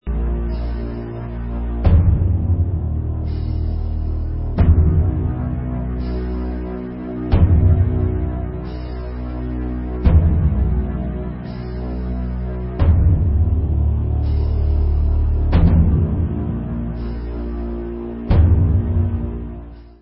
Extreme doom